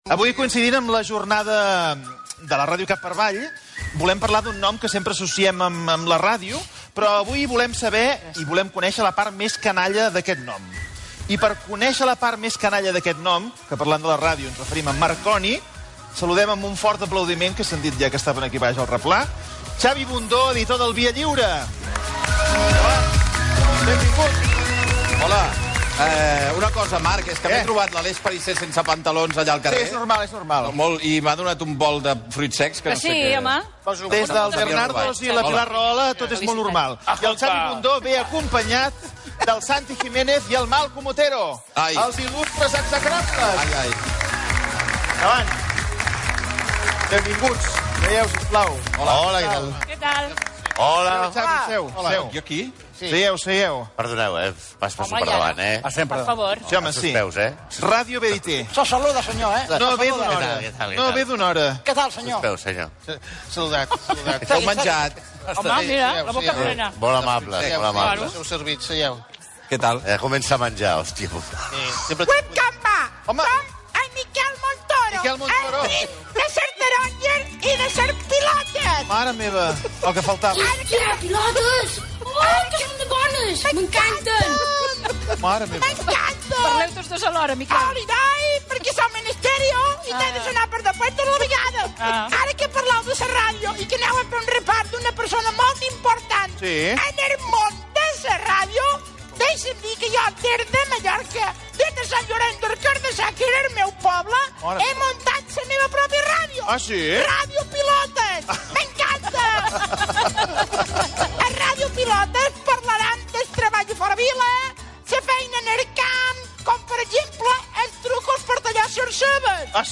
4ede3c2ef3e3251decf952018ded0acec9e10385.mp3 Títol RAC 1 Emissora RAC 1 Barcelona Cadena RAC Titularitat Privada nacional Nom programa Versió RAC 1 Descripció La ràdio cap per avall. Secció "Il·lustres execrables" del programa "Via lliure": Ràdio Pilota, comentari sobre els responsables de la secció, la figura de Guglielmo Marconi Gènere radiofònic Entreteniment